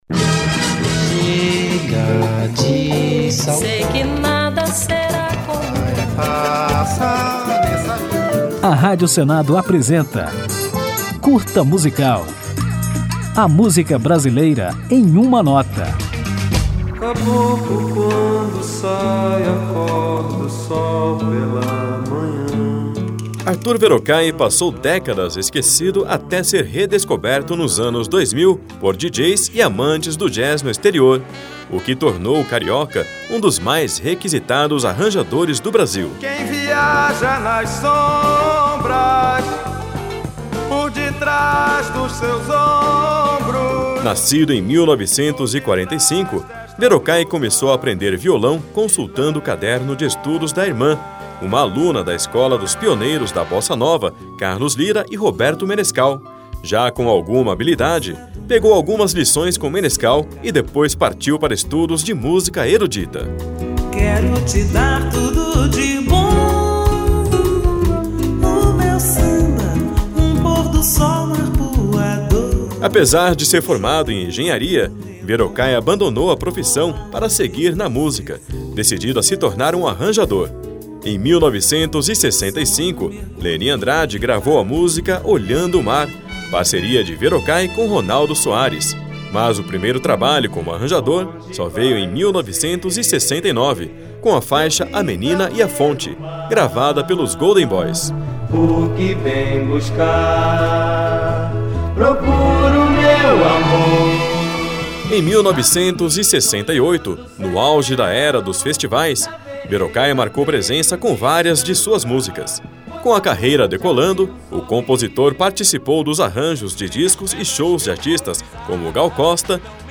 Neste curta musical você vai conhecer a trajetória surpreendente desse grande artista e ainda ouvir a música Na Boca do Sol, presente no primeiro disco de Arthur Verocai.